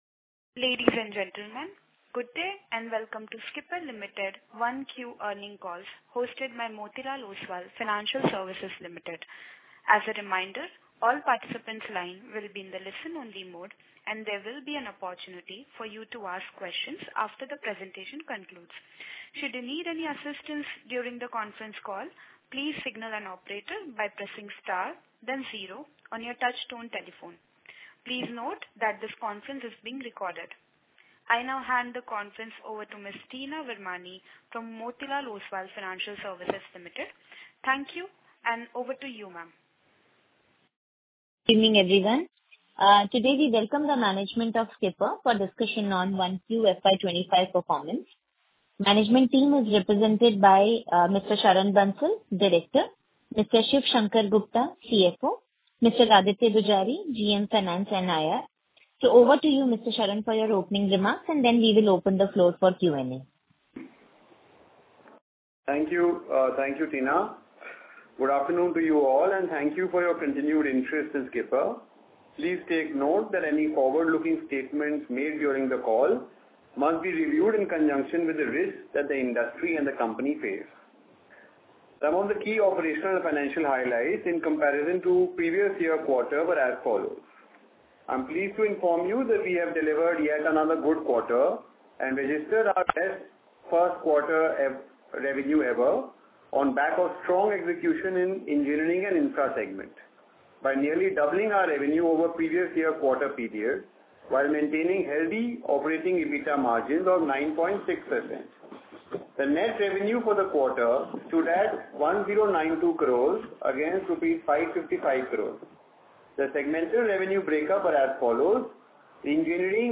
Intimation of Audio recording of Skipper Limited Q1FY 24-25 Earnings Con Call.